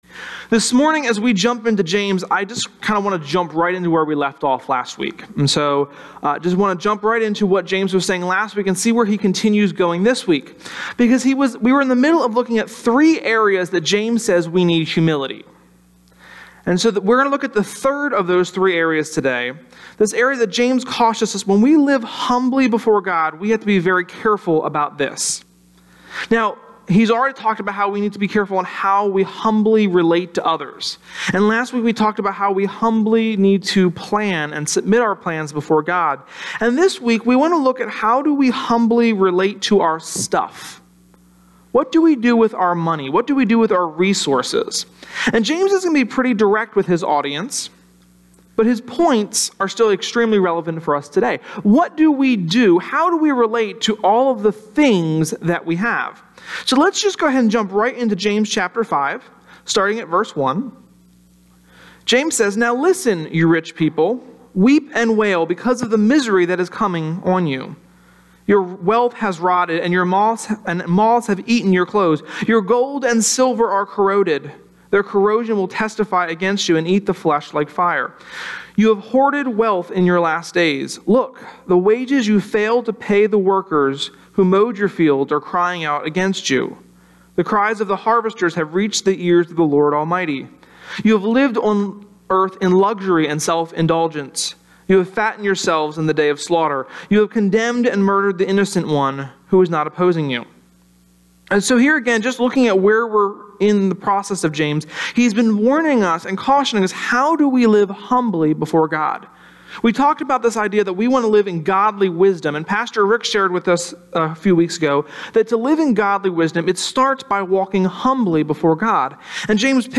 Sermon-6.18.17.mp3